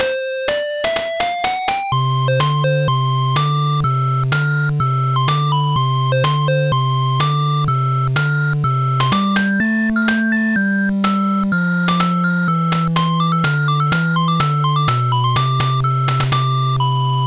The depressing ones are supposed to sound depressing, sorta like that swamp area in Majora's Mask, the Souther Swamp I believe.